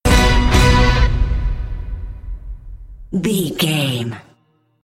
Epic / Action
Aeolian/Minor
cello
violin
percussion
synth effects
driving drum beat